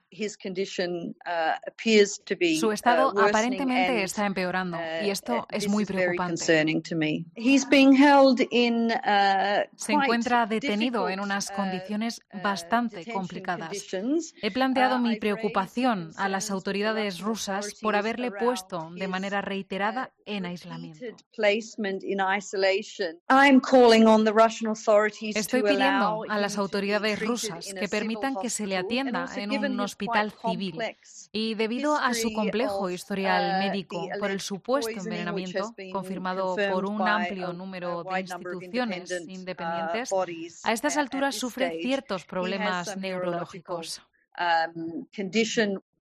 Alice Edwards, relatora especial de la ONU contra la tortura, en COPE: "Navalni se está deteriorando”